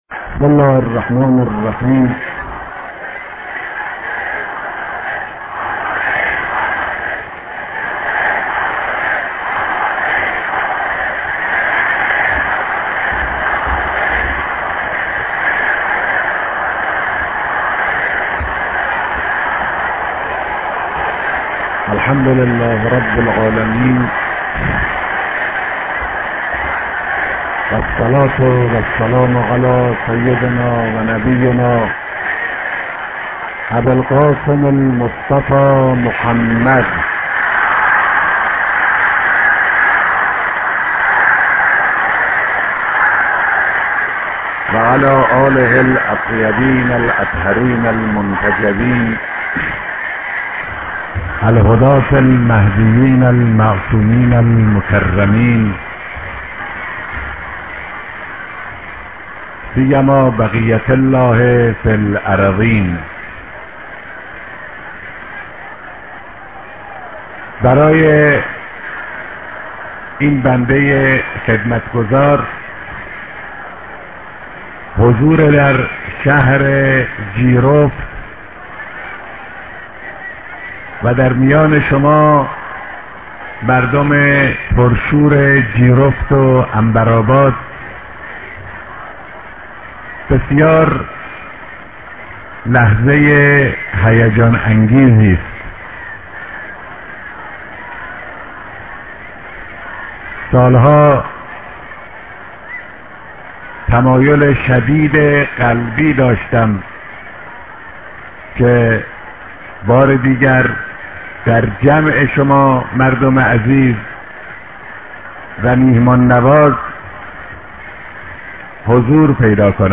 رهبر معظم انقلاب در ديدار مردم جيرفت